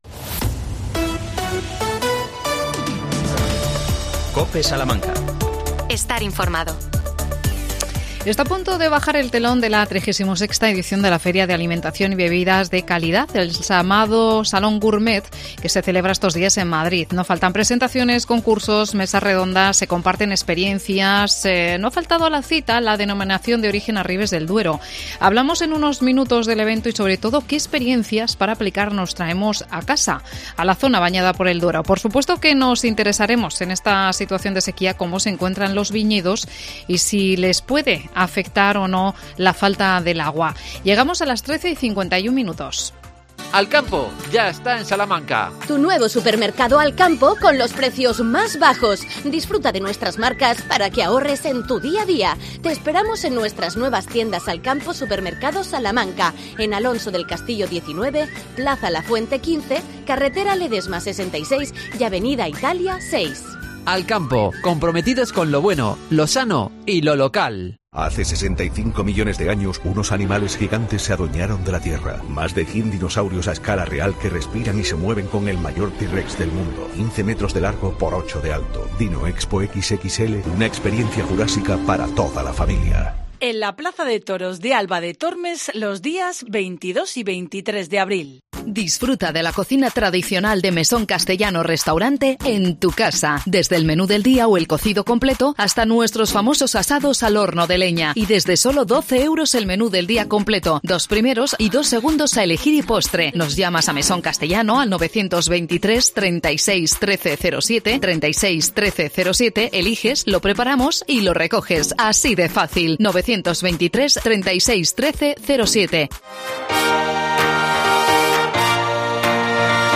La D.O Arribes del Duero en el Salón Gourmet. Entrevistamos